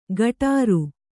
♪ gaṭāru